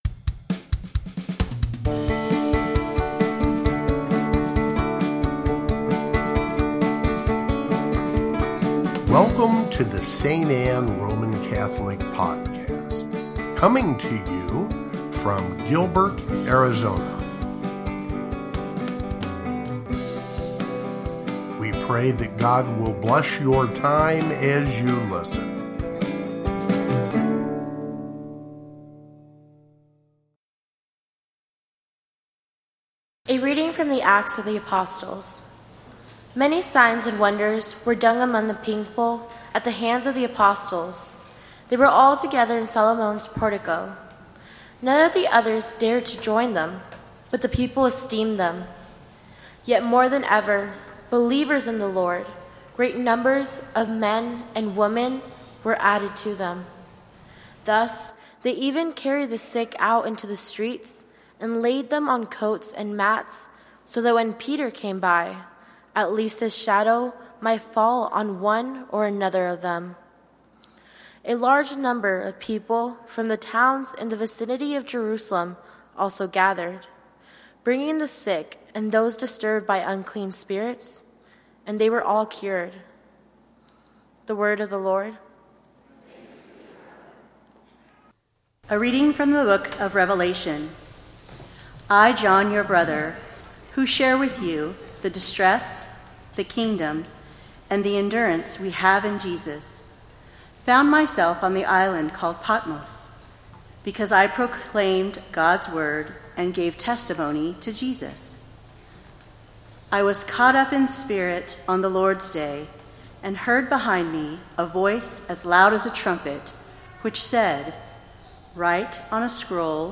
Second Sunday of Easter (Readings)
Gospel, Readings, Easter